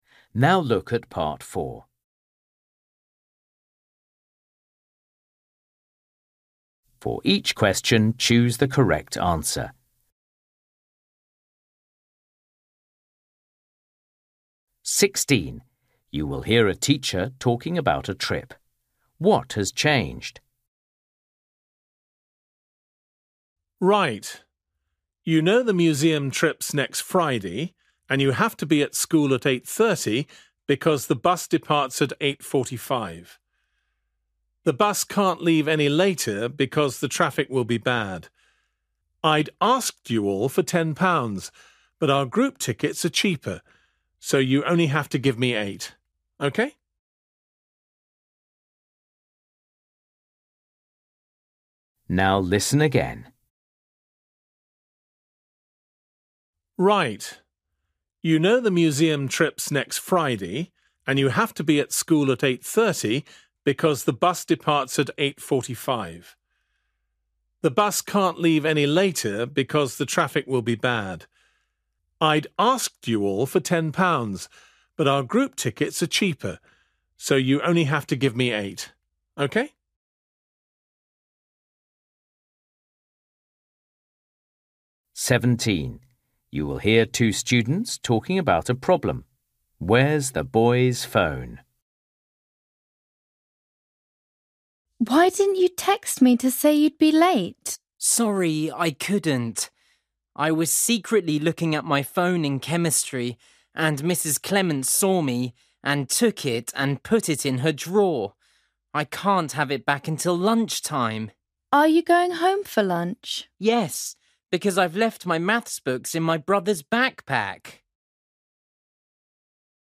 16   You will hear a teacher talking about a trip.
17   You will hear two students talking about a problem.
18   You will hear a boy talking about buying some boots.
19   You will hear a girl talking about playing tennis.